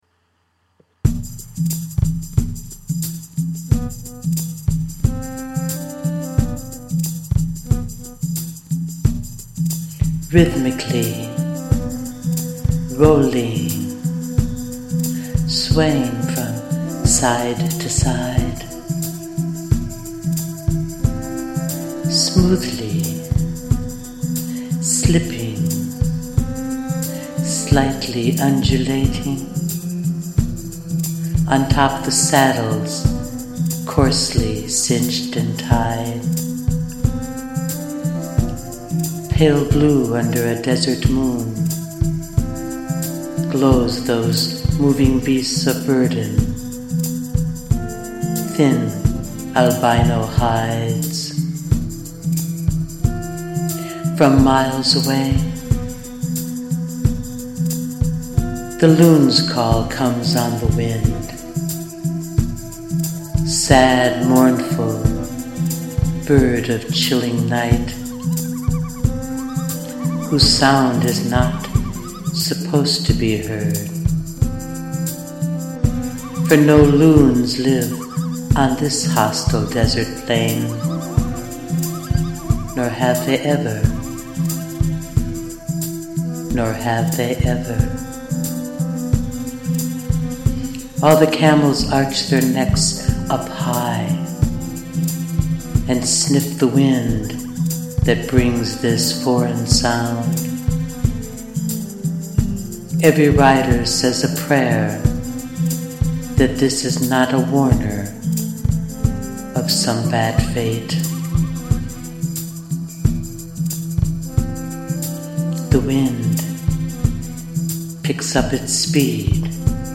Close your eyes, and take a moonlight caravan ride, where strange sounds assault your imagination.
quite sensual and exotic.